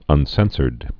(ŭn-sĕnsərd)